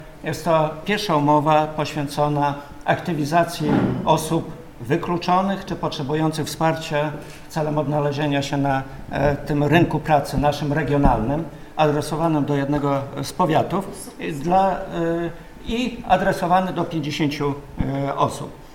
Jak mówił Bogdan Dyjuk, członek zarządu województwa, jest to pierwszy tak precyzyjnie ukierunkowany projekt: